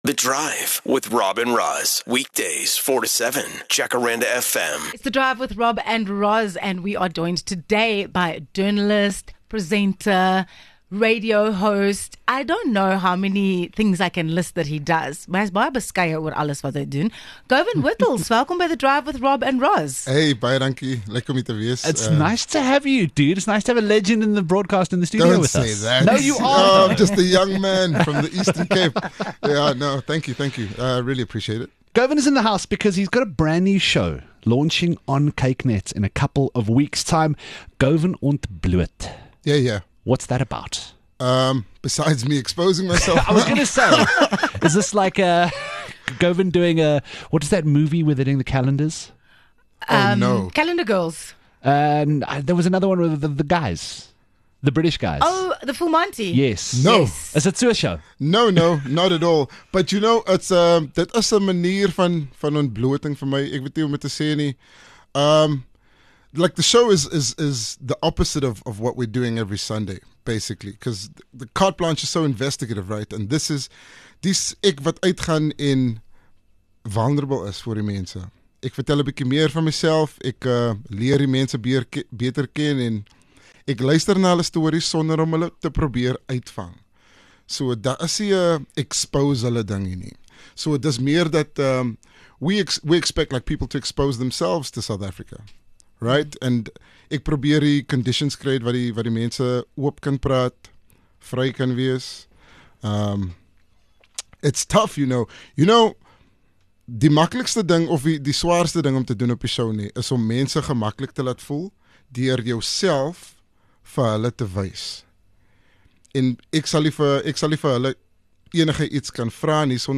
30 Jul INTERVIEW